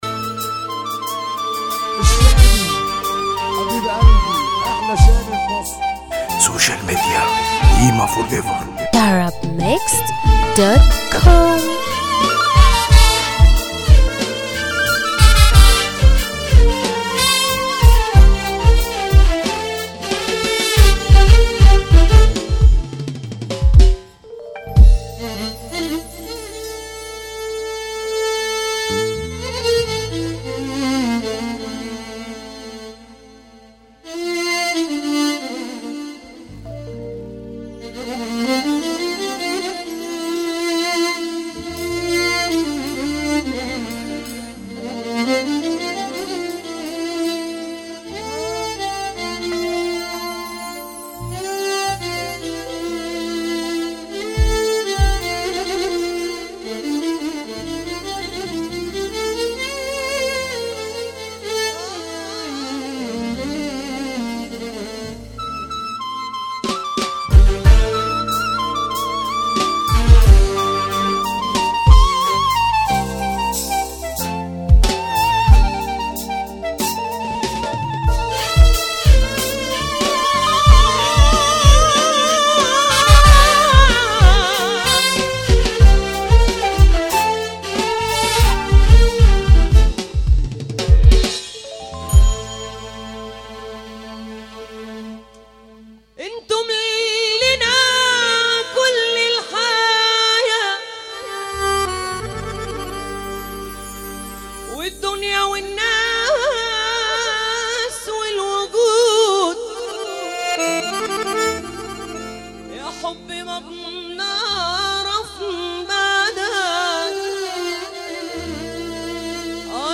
موال
حزينا جدا